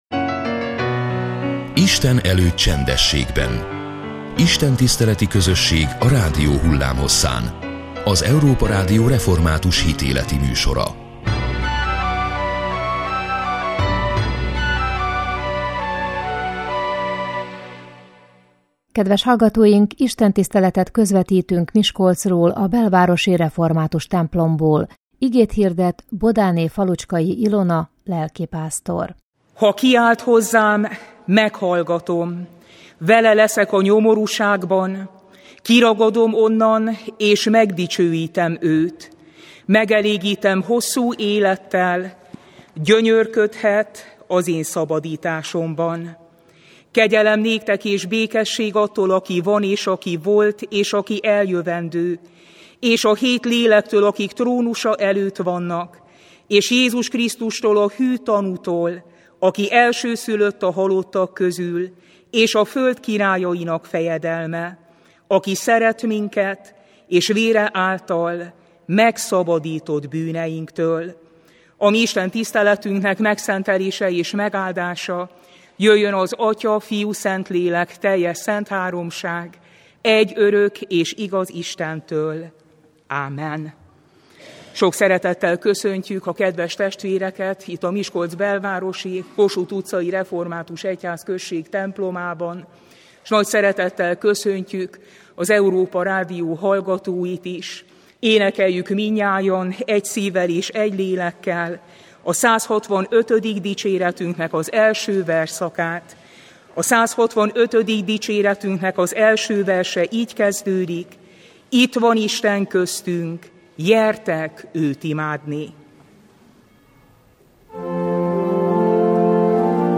Istentiszteletet közvetítettünk Miskolcról, a belvárosi református templomból.